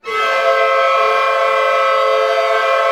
Index of /90_sSampleCDs/Roland LCDP08 Symphony Orchestra/ORC_ChordCluster/ORC_Clusters
ORC CLUST00L.wav